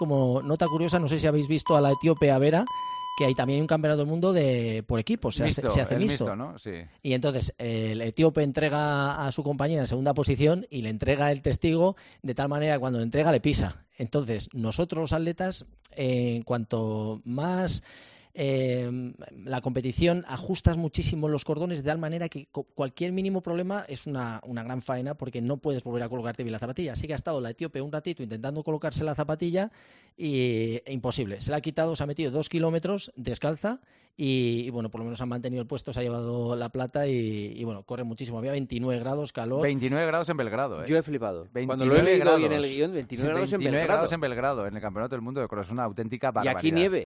Desvela en El Partidazo de COPE cómo la etíope Birri Abera terminó colgándose una medalla de plata en el Mundial tras recorrer en estas condiciones dos kilómetros